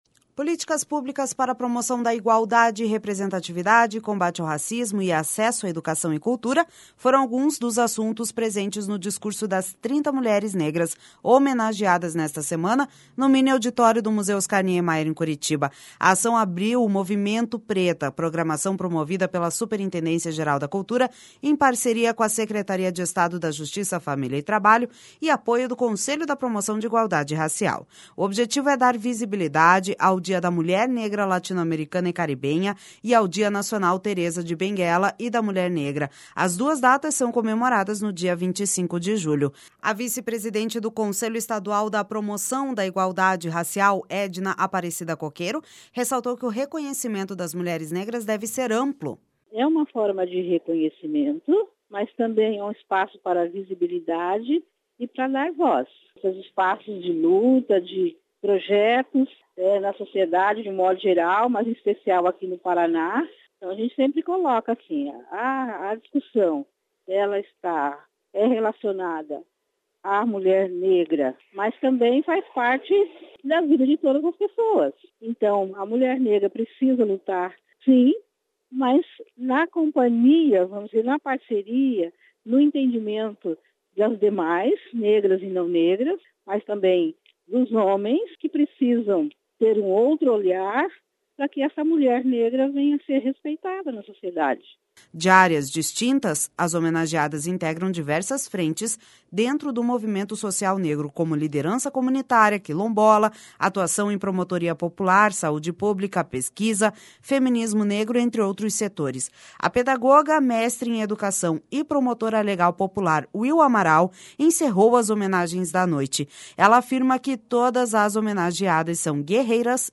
Políticas públicas para promoção da igualdade, representatividade, combate ao racismo e acesso à educação e cultura foram alguns dos assuntos presentes no discurso das 30 mulheres negras homenageadas nesta semana, no miniauditório do Museu Oscar Niemeyer, em Curitiba.